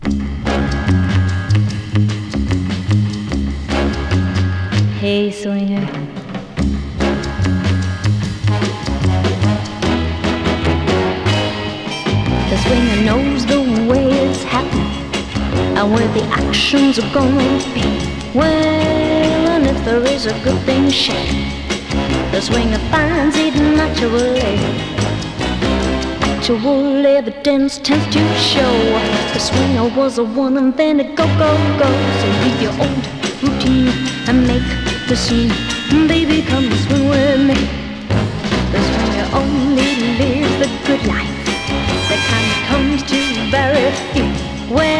Rock, Pop, Vocal　Australia　12inchレコード　33rpm　Mono